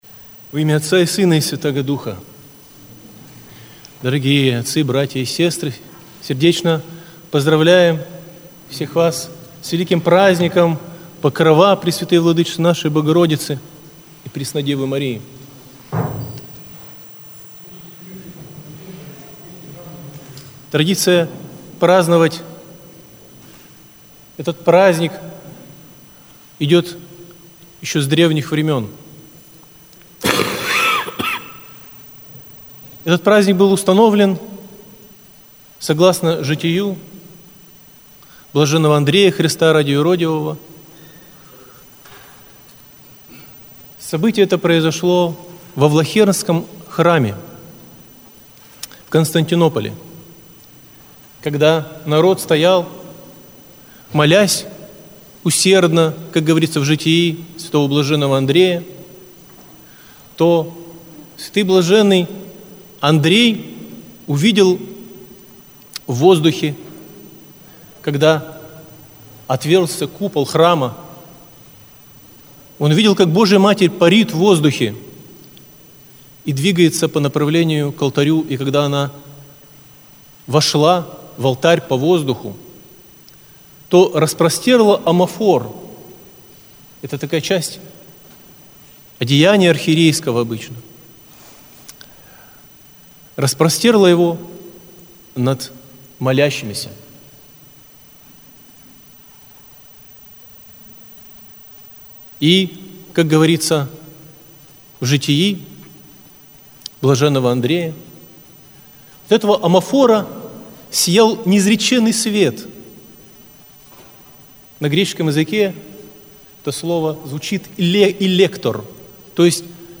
Аудиозапись проповеди